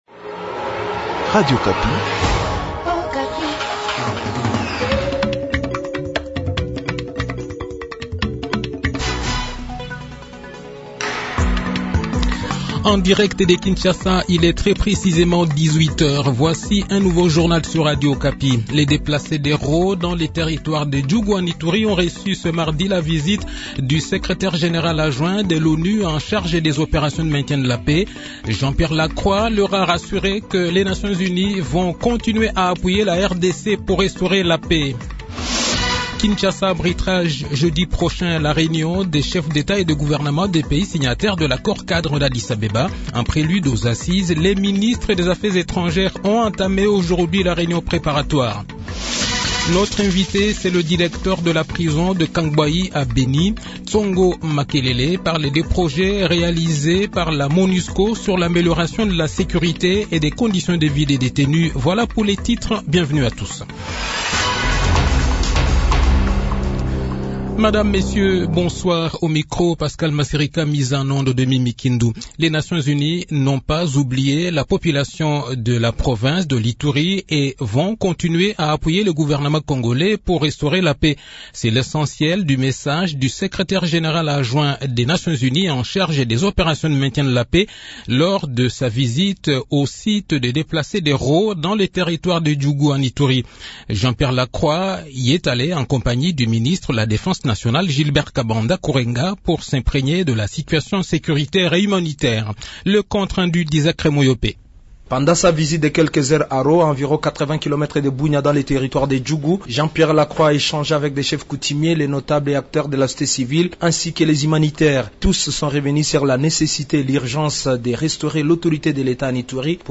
Le journal de 18 h, 22 février 2022